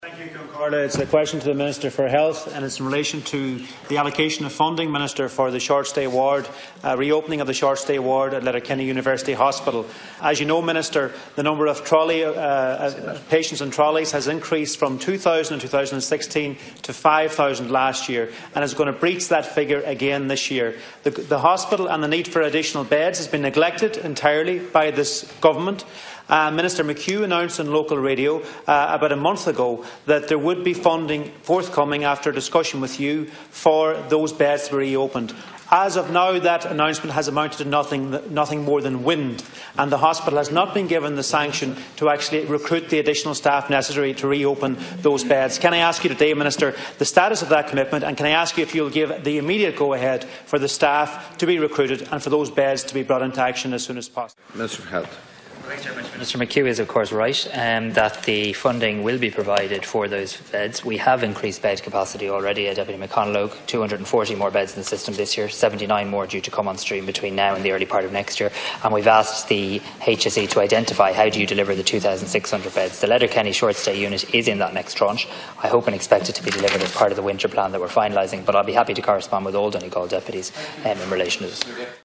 While Minister Harris could not confirm exactly when the funding would be made available, he says it is part of the Government’s Winter Plan: